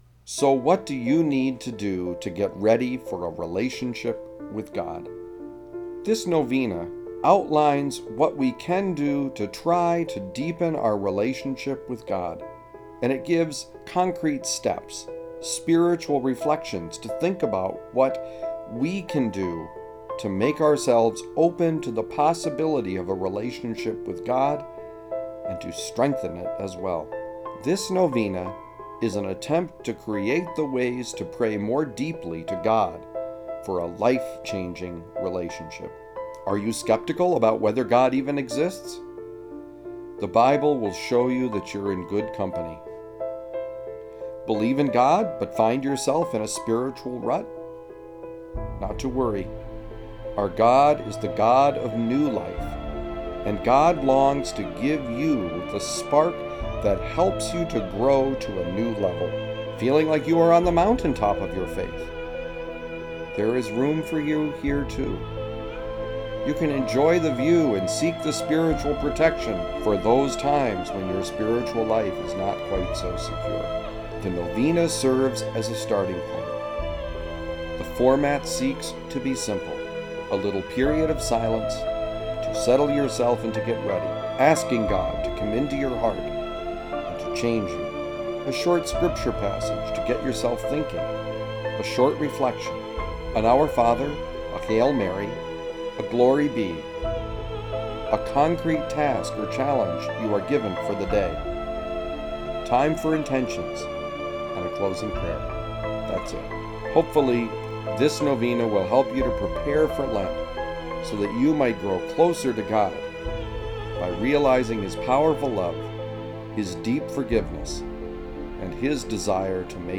Music used in the podcast is Piano Ballad by Rafael Krux
Lent-Novena-Promo.mp3